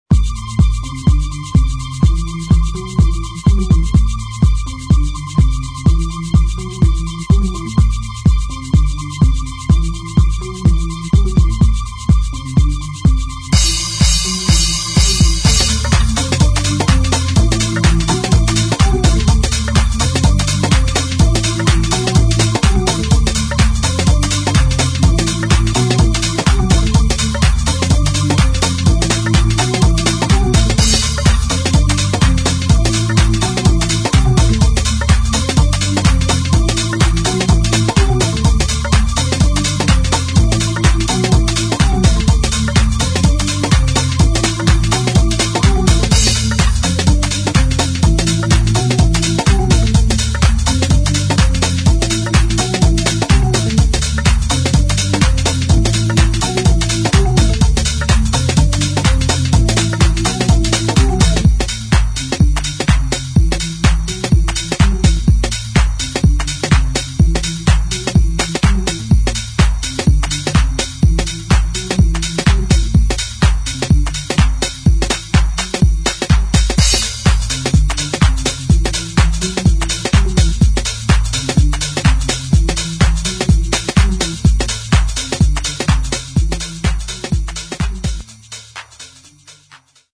[ DEEP HOUSE | TECHNO ]